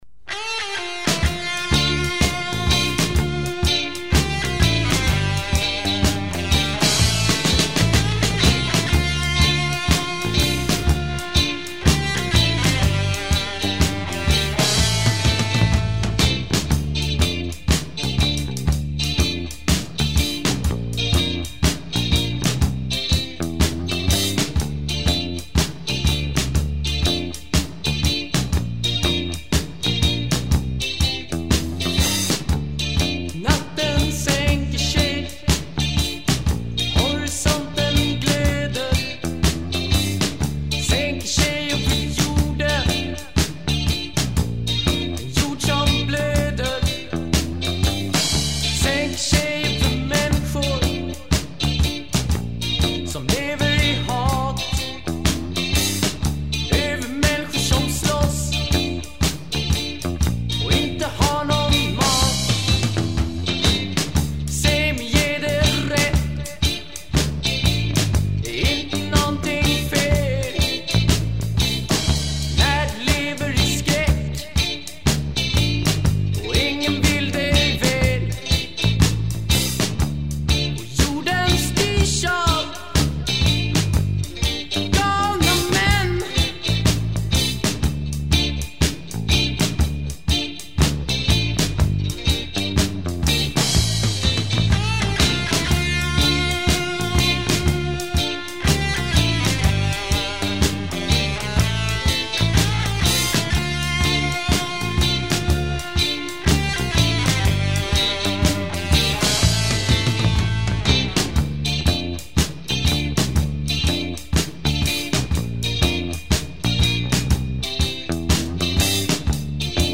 Drums
Guitar, Voice
Bass